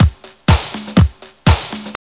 B6HOUSE123.wav